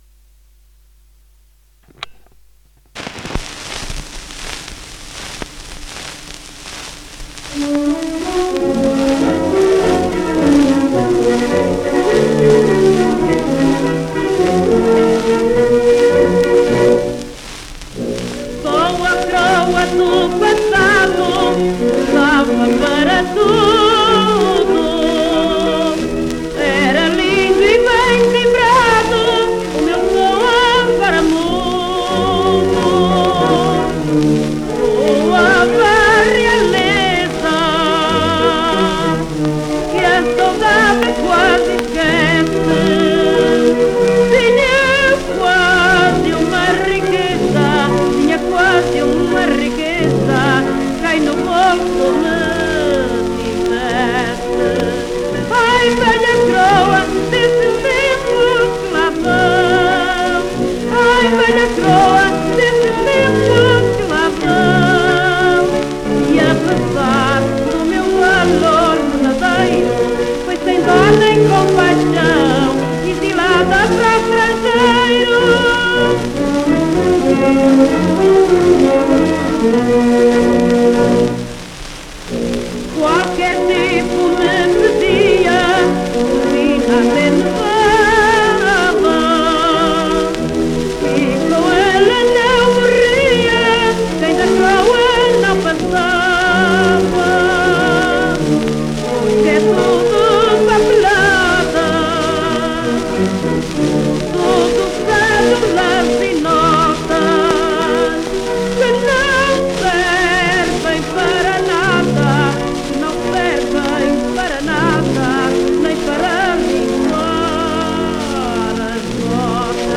inetmd-fcsh-ifpxx-mntd-audio-fado_da_coroa_fado-4158.mp3